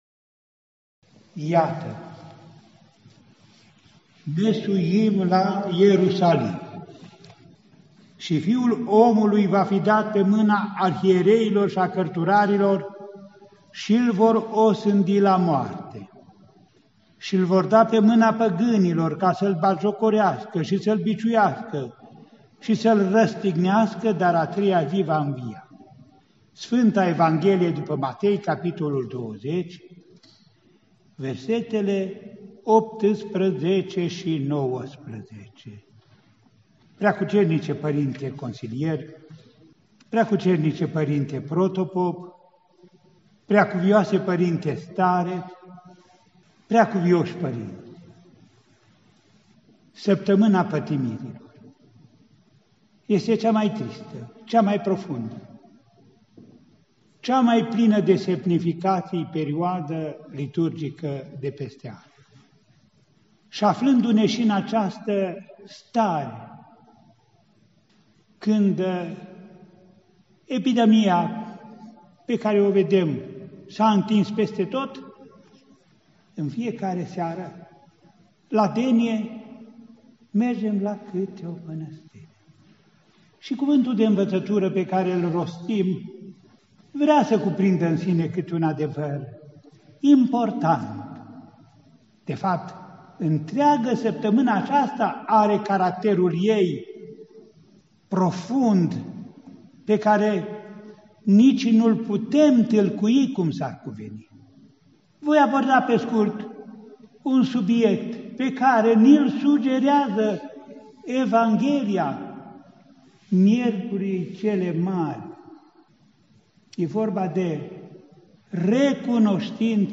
Mitropolitul Andrei – Predică la Denia din Sfânta și Marea Miercuri – 14 aprilie 2020
Cuvântul de învățătură al Înaltpreasfințitului Părinte Andrei, Arhiepiscopul Vadului, Feleacului și Clujului și Mitropolitul Clujului, Maramureșului și Sălajului, rostit cu prilejul oficierii Deniei din Sfânta și Marea Miercuri, la Mănăstirea Râșca Transilvană, județul Cluj, marți seara, 14 aprilie 2020.